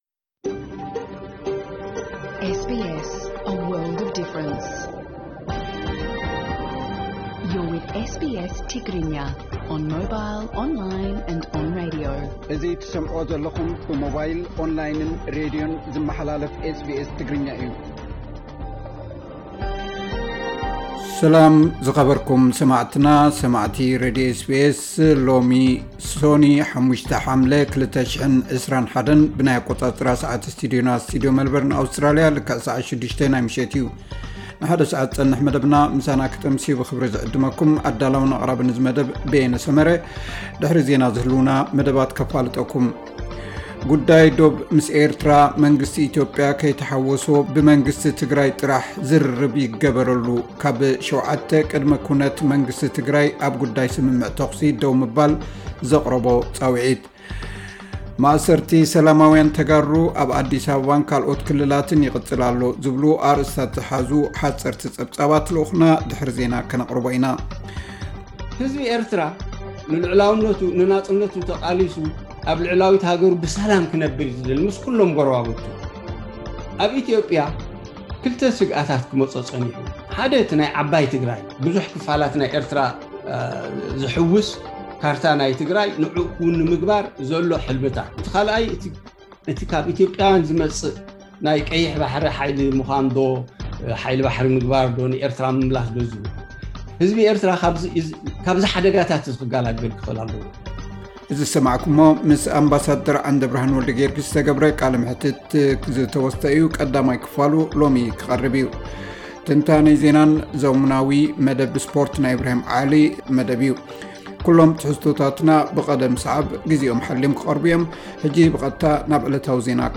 ዕለታዊ ዜና 5 ሓምለ 2021 SBS ትግርኛ